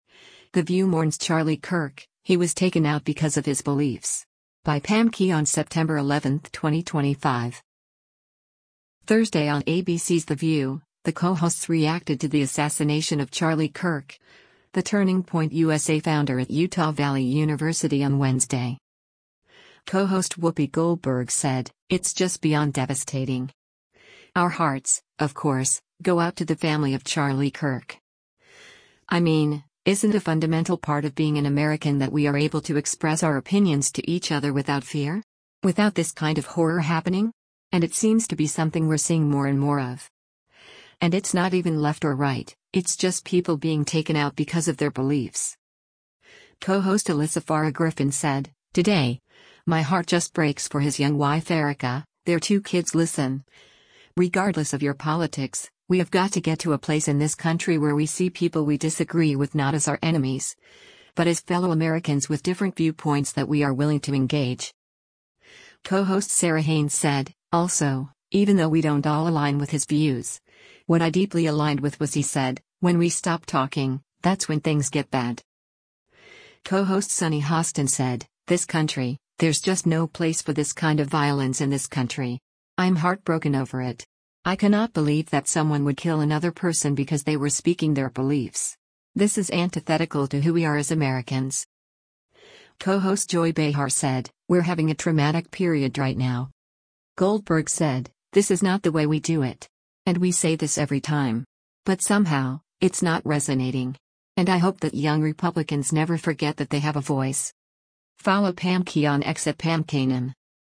Thursday on ABC’s “The View,” the co-hosts reacted to the assassination of Charlie Kirk, the Turning Point USA founder at Utah Valley University on Wednesday.